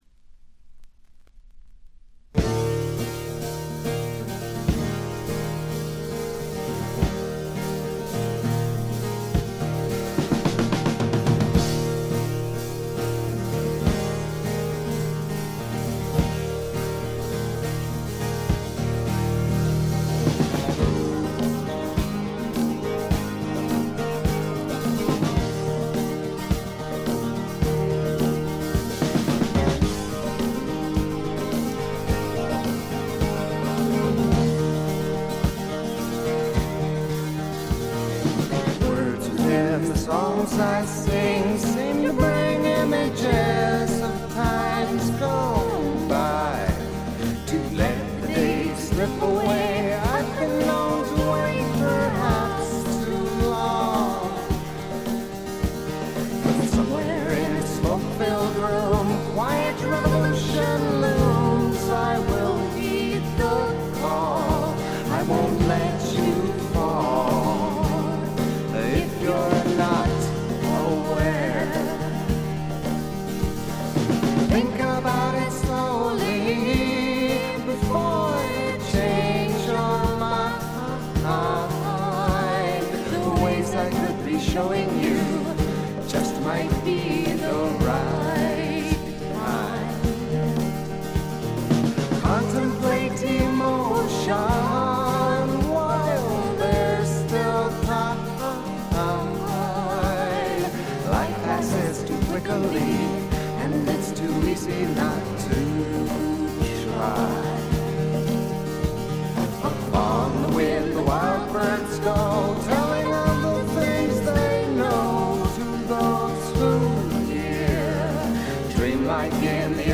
部分試聴ですが、軽微なバックグラウンドノイズにチリプチ少し。
70年代初頭の感覚が強い「あの感じの音」です。
試聴曲は現品からの取り込み音源です。